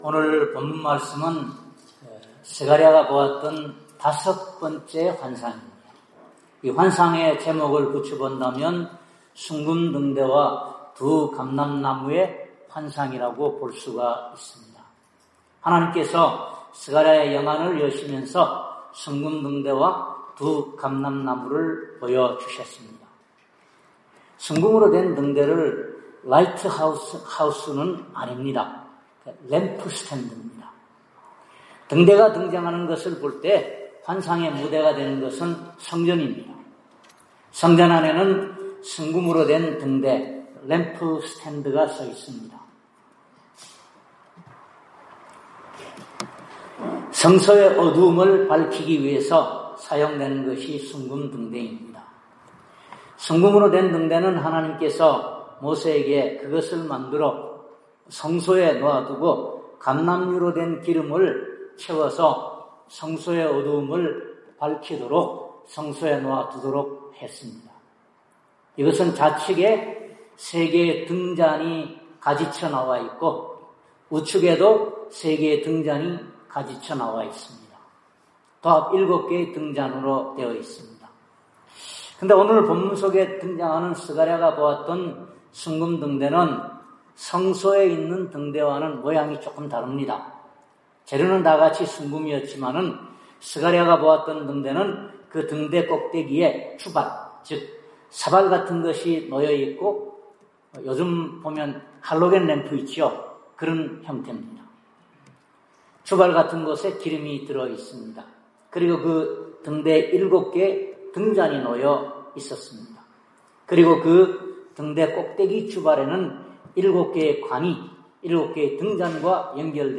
슥 4:1-14 Service Type: 주일예배 스가랴 4장은 스가랴가 보았던 다섯번째의 환상으로서 제목을 붙여 본다면 ‘순금등대와 두 감람나무의 환상’이라고 할 수 있습니다.